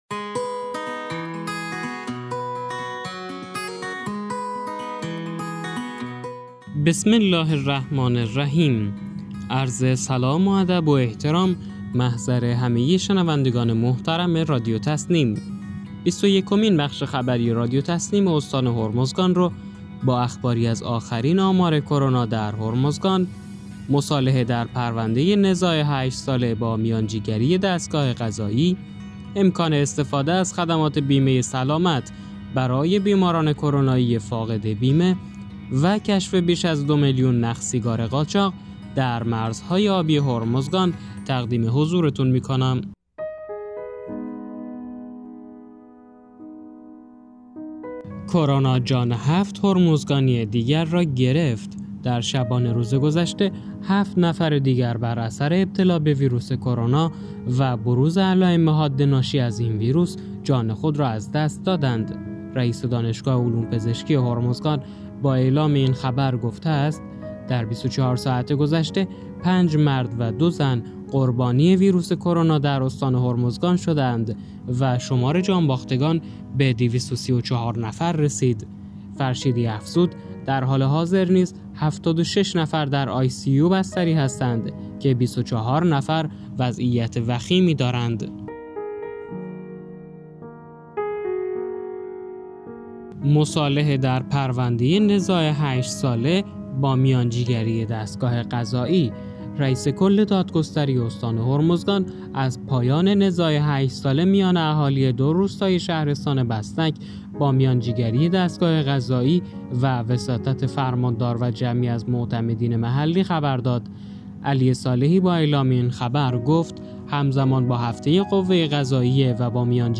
به گزارش خبرگزاری تسنیم از بندرعباس، بیست و یکمین بخش خبری رادیو تسنیم استان هرمزگان با اخباری از آخرین آمار کرونا در هرمزگان، مصالحه در پرونده نزاع 8 ساله با میانجیگری دستگاه قضایی، امکان استفاده از خدمات بیمه سلامت برای بیماران کرونایی فاقد بیمه و کشف بیش از 2 میلیون نخ سیگار قاچاق در مرزهای آبی هرمزگان منتشر شد.